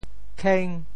“擎”字用潮州话怎么说？
kheng5.mp3